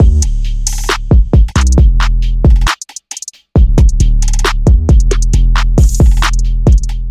陷阱鼓循环
标签： 陷阱 808 节拍 鼓环
声道立体声